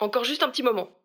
VO_ALL_Interjection_05.ogg